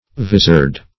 Visored \Vis"ored\, a.